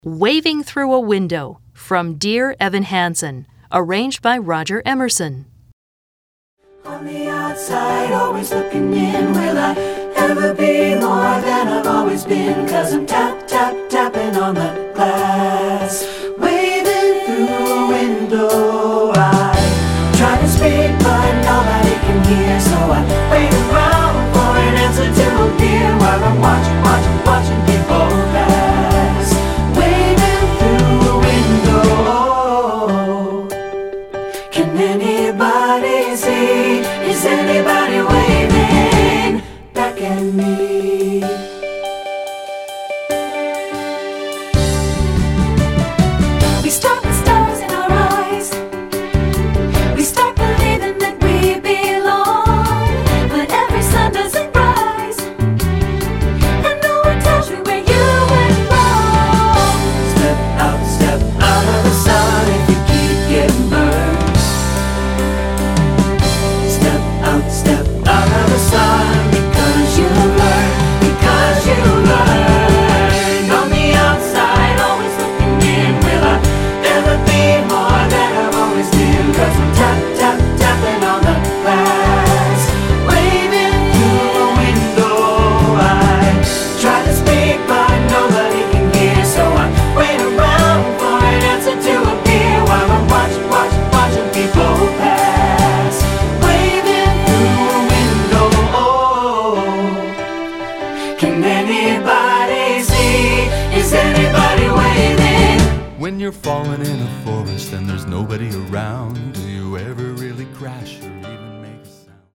Choral Movie/TV/Broadway